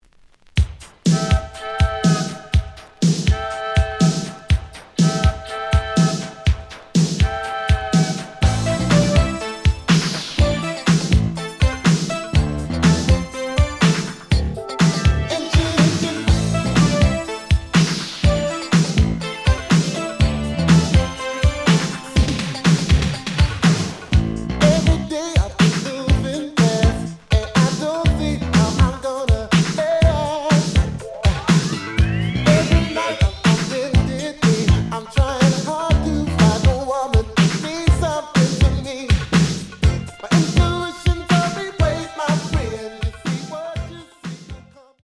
試聴は実際のレコードから録音しています。
●Genre: Disco
●Record Grading: VG+ (A面のラベルに書き込み。多少の傷はあるが、おおむね良好。)